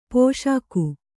♪ pōṣāku